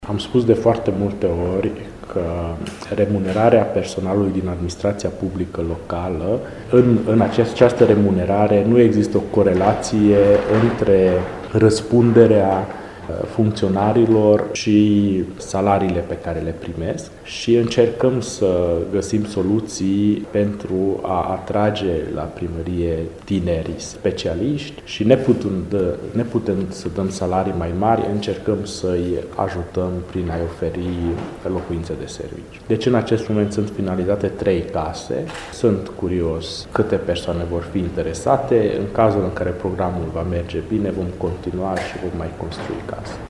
Primarul municipiului Sfântu Gheorghe, Antal Arpad a declarat că este vorba despre trei case construite pe strada Borviz care vor fi date în chirie, menţionând că intenţia autorităţilor locale este de a continua construirea de locuinţe de serviciu pentru specialiştii care lucrează în municipiu.
insert-primar-.mp3